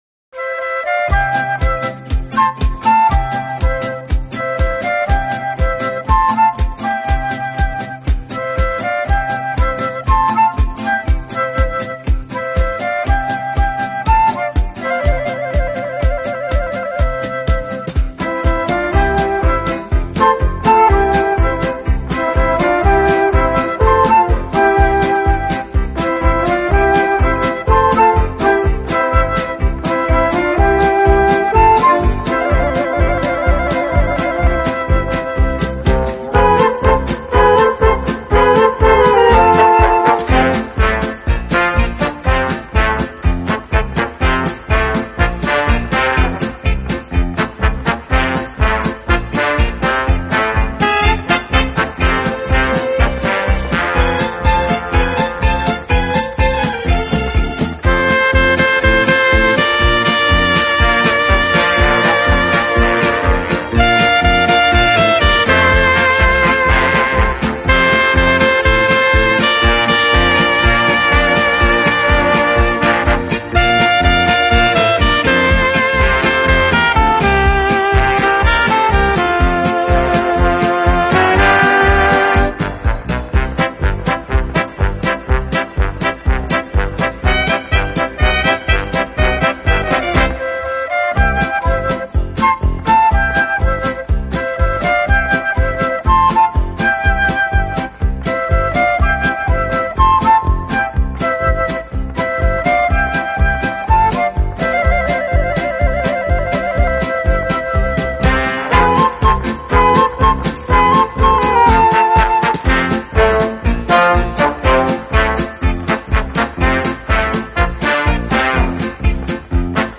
该乐队以两支低音萨克管作为主奏乐器，在